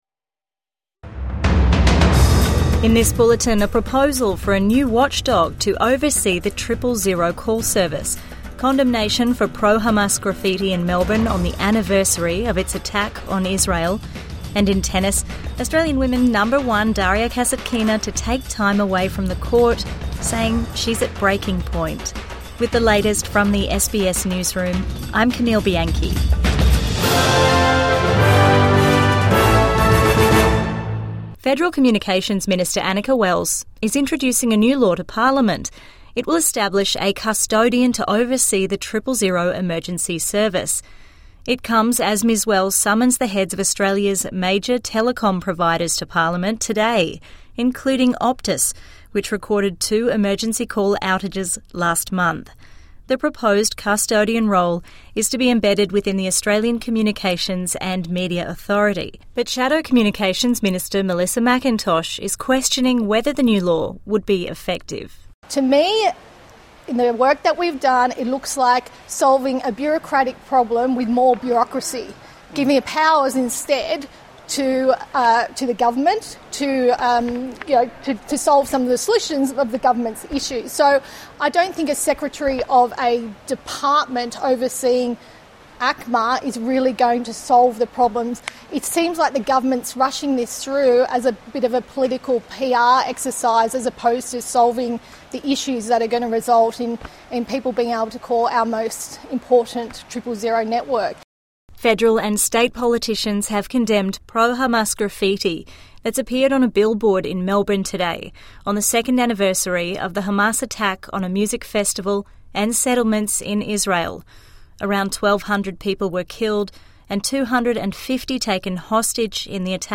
"Terrorist propaganda" condemned on attack anniversary | Evening News Bulletin 7 October 2025